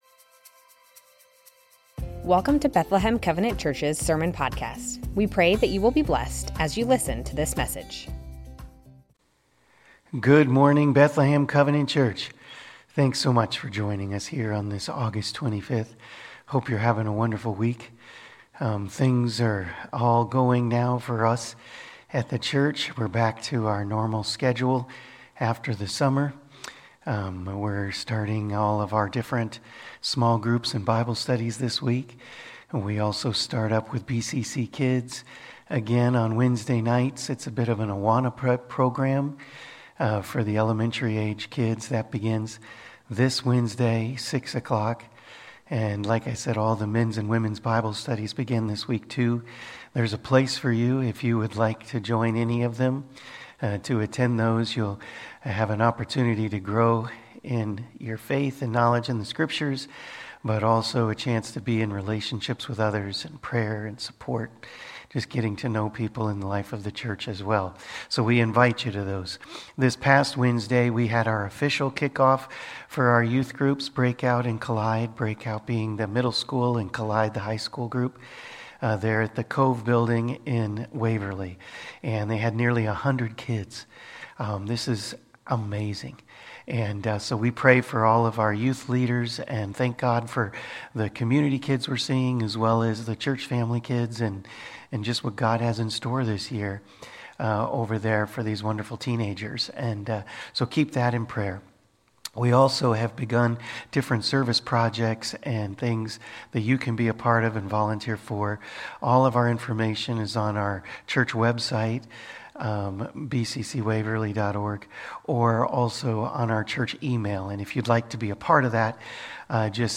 Bethlehem Covenant Church Sermons Living to please God - 1 Thessalonians 2:1-8 Aug 25 2024 | 00:40:31 1x 00:00 / 00:40:31 Subscribe Share Spotify RSS Feed Share Link Embed